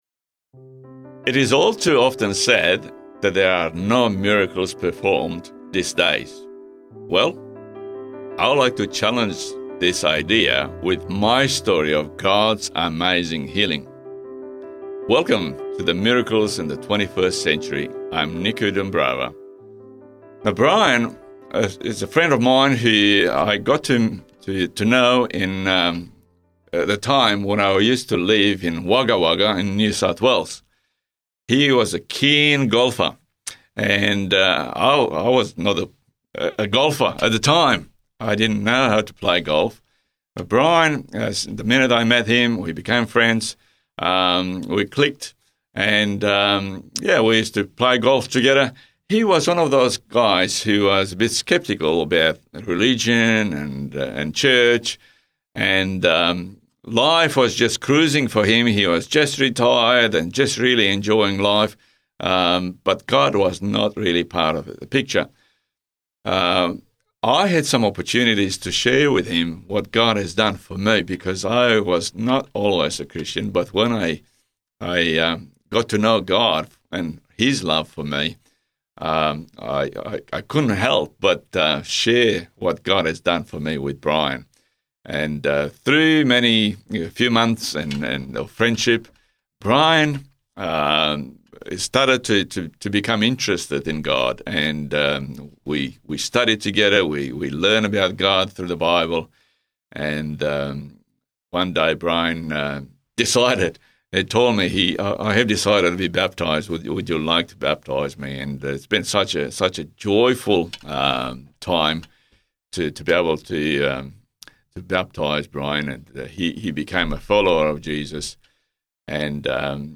Music Credits: